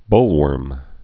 (bōlwûrm)